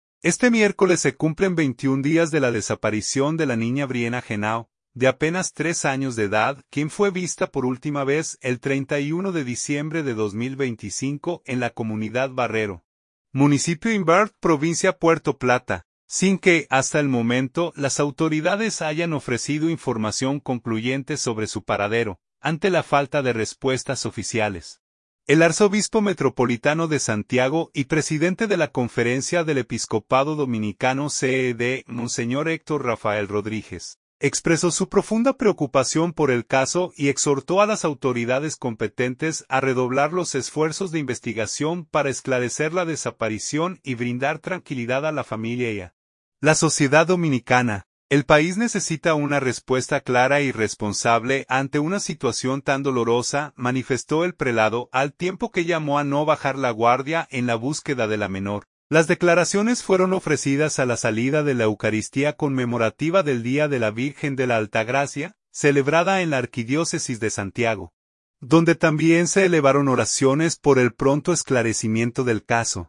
Las declaraciones fueron ofrecidas a la salida de la eucaristía conmemorativa del Día de la Virgen de la Altagracia, celebrada en la arquidiócesis de Santiago, donde también se elevaron oraciones por el pronto esclarecimiento del caso.